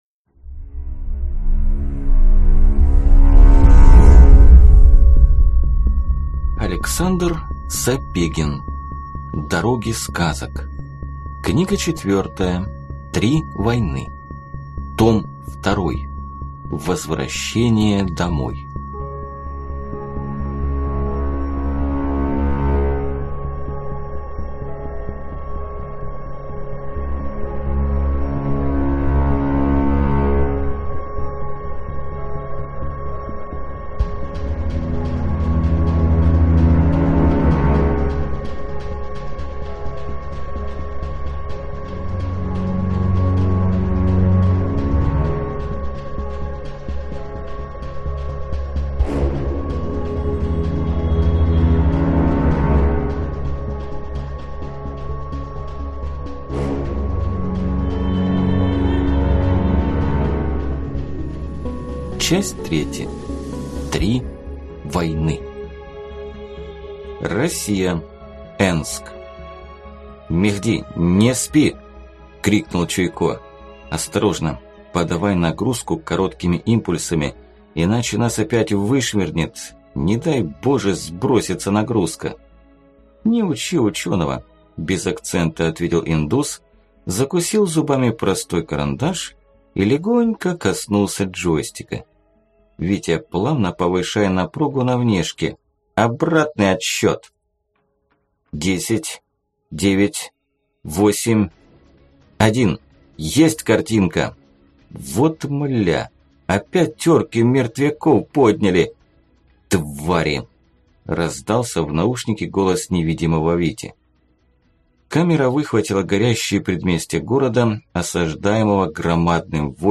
Аудиокнига Три войны. том 2: Возвращение домой | Библиотека аудиокниг